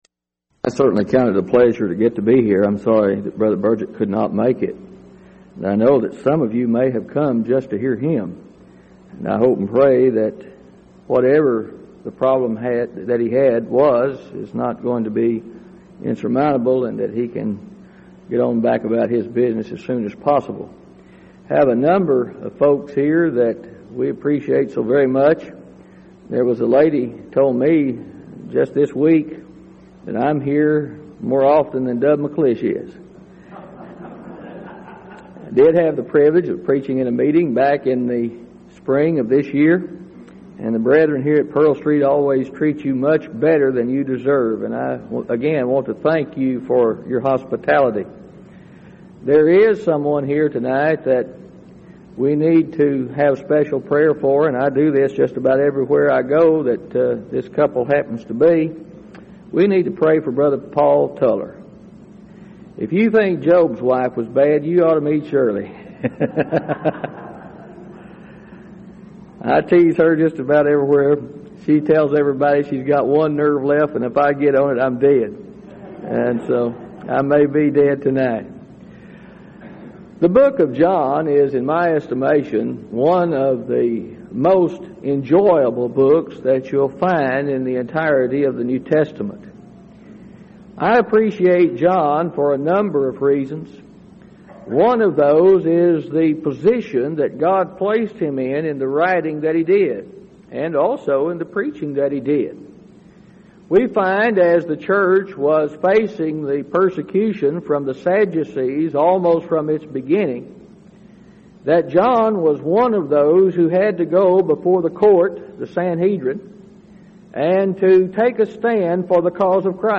Event: 1999 Denton Lectures Theme/Title: Studies in the Book of John